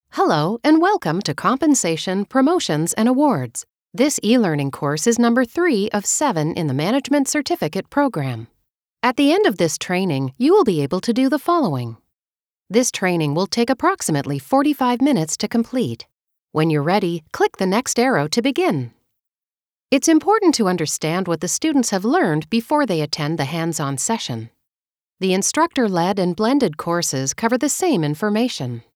E-Learning